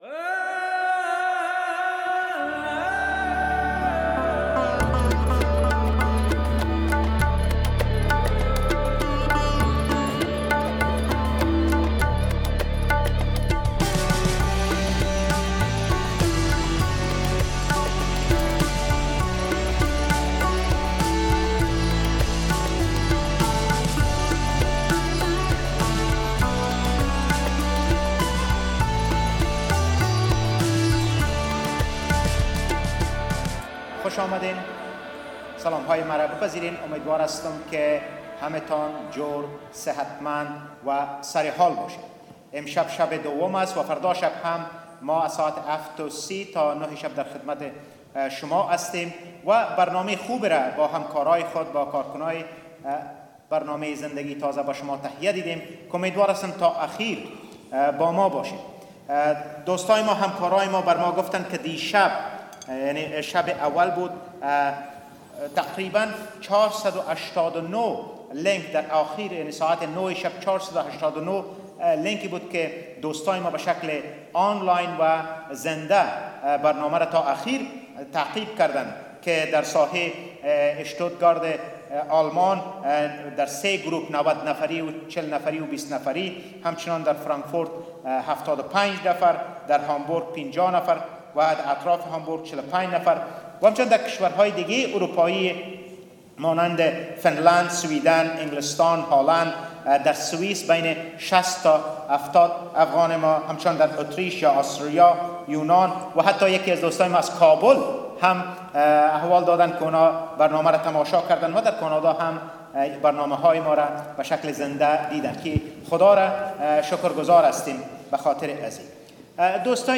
Second night of Christ4Afghans - a live evangelistic broadcast event for Afghans in their own language.